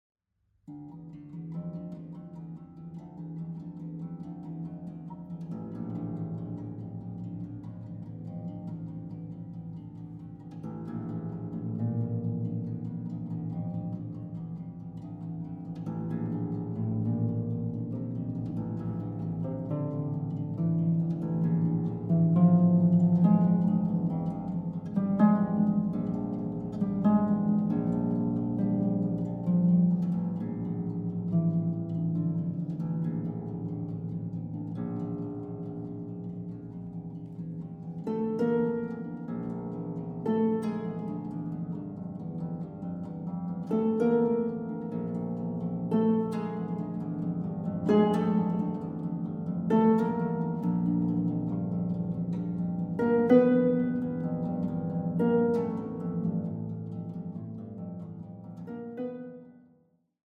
Contemporary Music for Harp
Harp